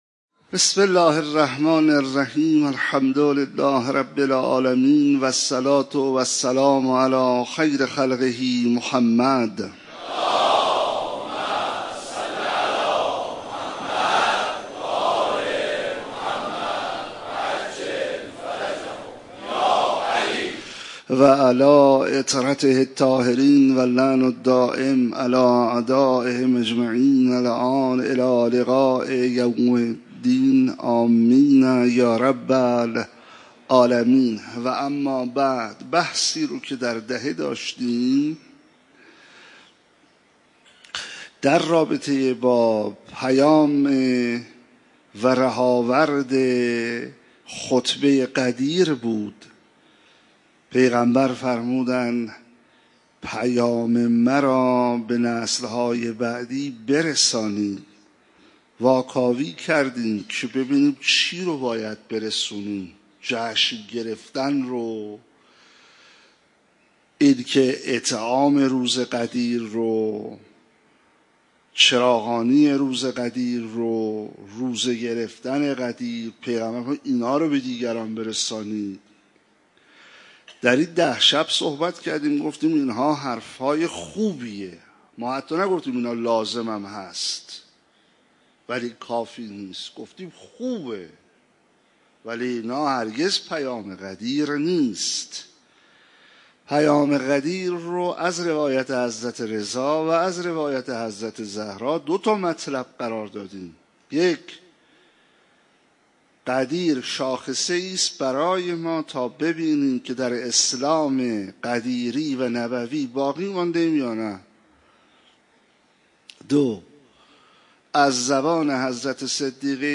صوت کامل این سخنرانی را اینجا بشنوید: مرورگر شما از ویدئو پشتیبانی نمی‌کند.